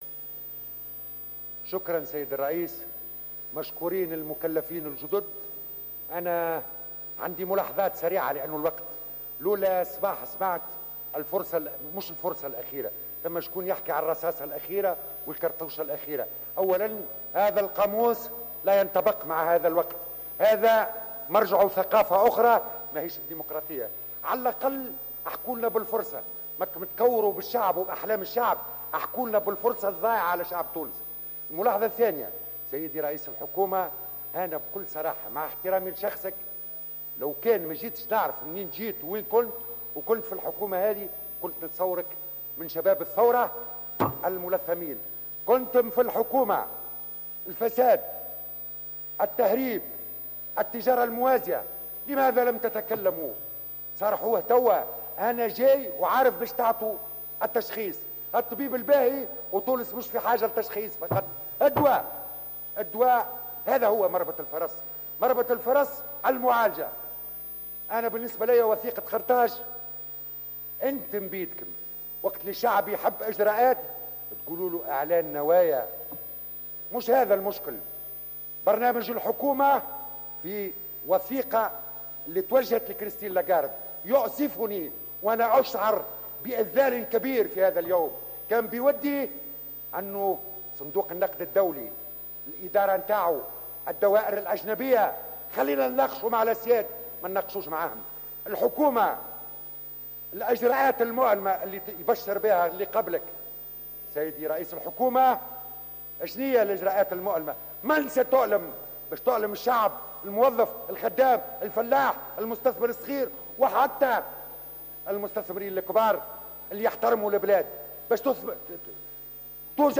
قال النائب عن الجبهة الشعبية، عمار عمروسية خلال جلسة منح الثقة لحكومة يوسف الشاهد إن تونس ليست في حاجة إلى طبيب وتشخيص وإنما في حاجة إلى الدواء.